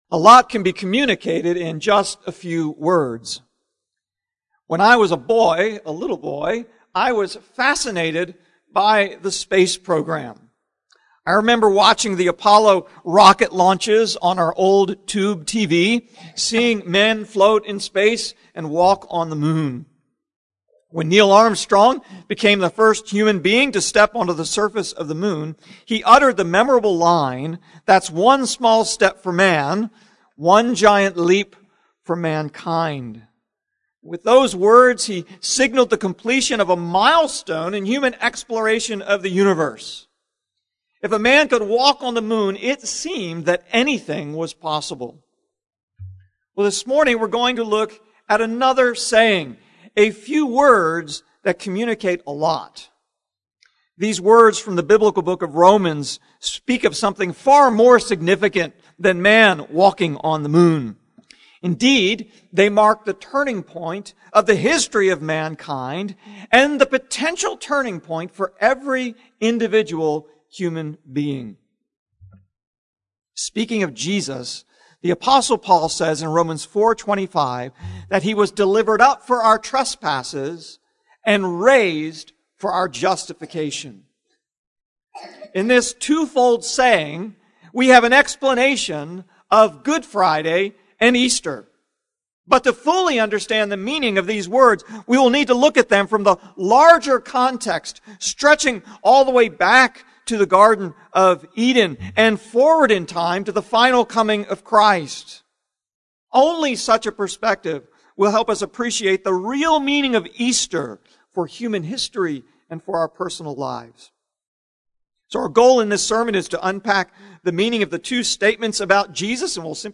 Jesus: Raised for Our Justification (Easter Message) - Covenant Presbyterian Church